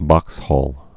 (bŏkshôl)